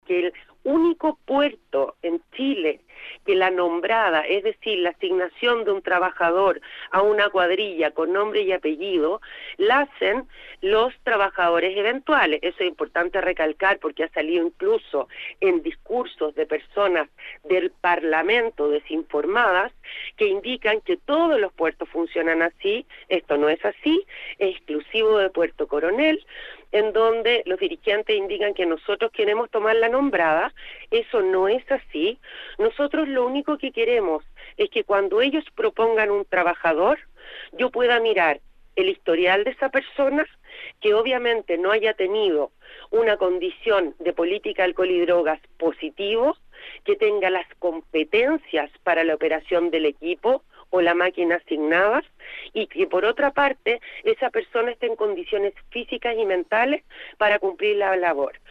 puerto-coronel-6.mp3